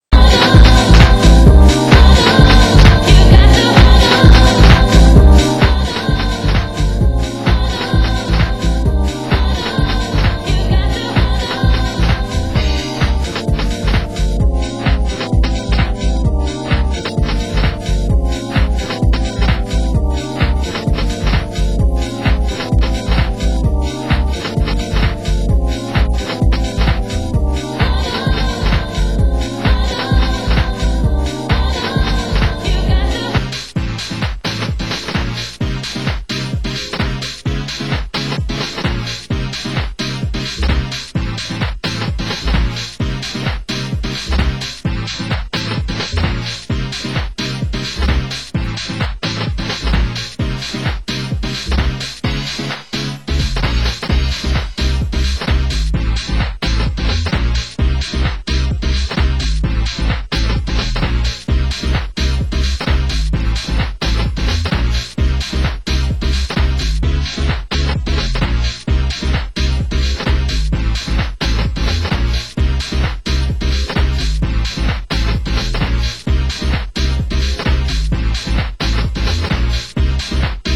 Genre: Drum & Bass